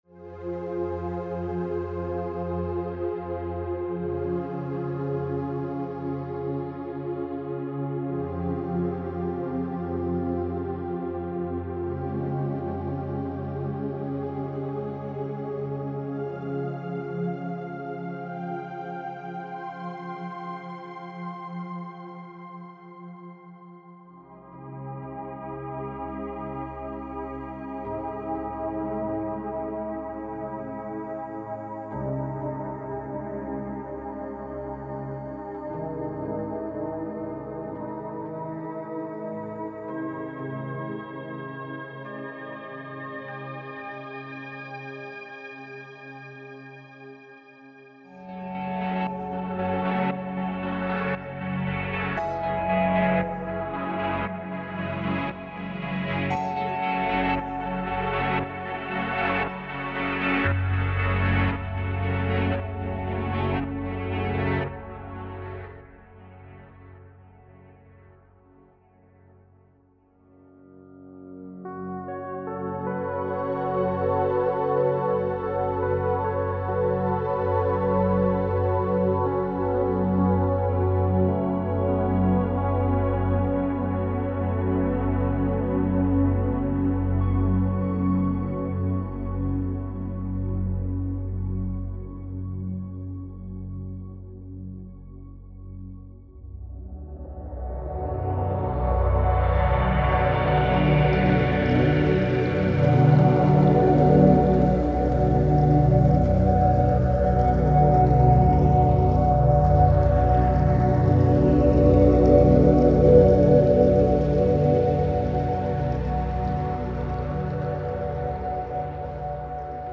Der Pure D16/24B basiert auf der subtraktiven Synthese nutzt dazu allerdings keine Oszillatoren sondern verwendet drei Sampler Einheiten, hier können die schon reichlich mitgelieferten WAV Loops oder auch eigene Sampleloops eingesetzt werden.
Der Sound des Pure D16/24B ist fantastisch und ich musste erst einmal den Mastervolume herunter schrauben da meine Monitore schon leichte Rauchzeichen von sich gaben, will damit sagen das Teil macht wahrlich Druck.